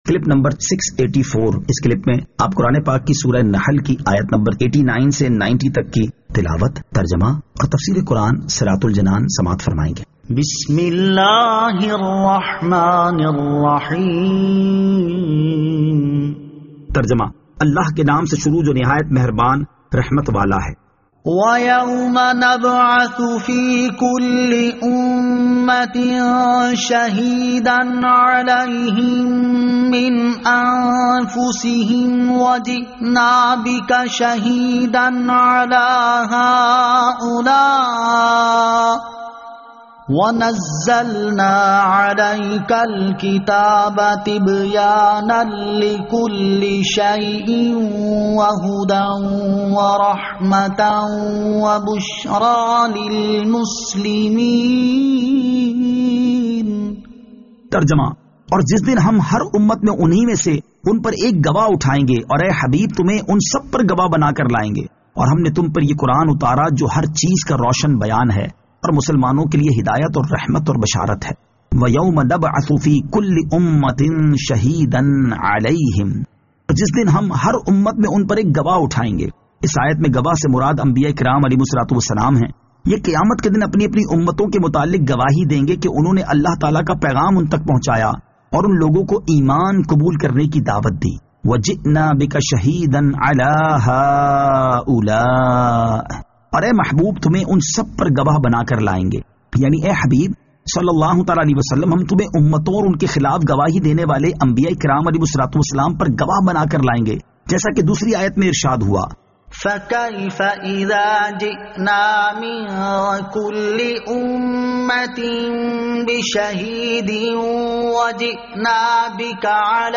Surah An-Nahl Ayat 89 To 90 Tilawat , Tarjama , Tafseer